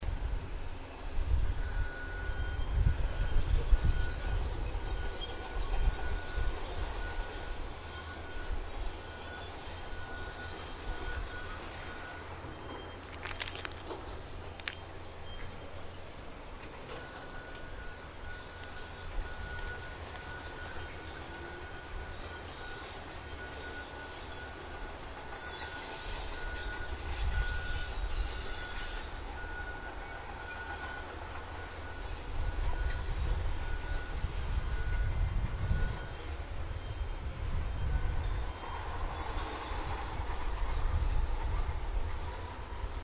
Grove ambient 31.mp3 construction sounds
scrapes of his shoes on bark